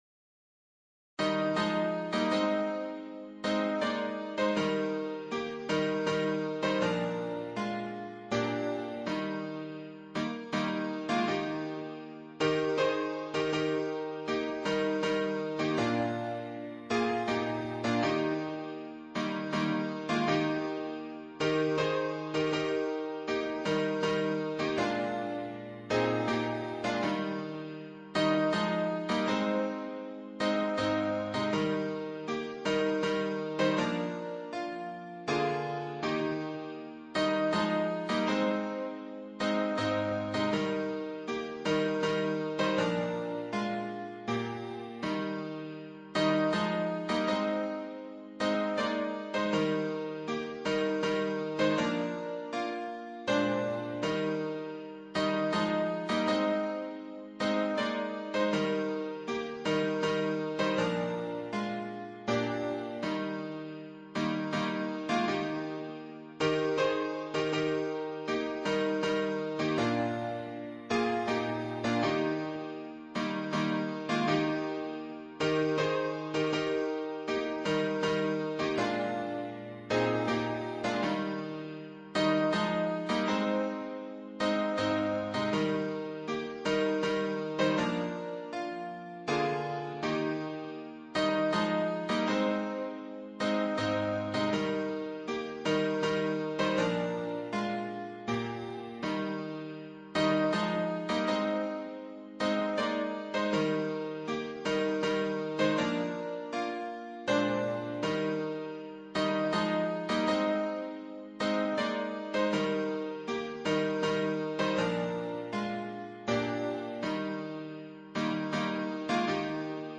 伴奏
原唱音频